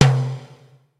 Percusión 14: timbal 6
membranófono
timbal
percusión
electrónico
golpe
sintetizador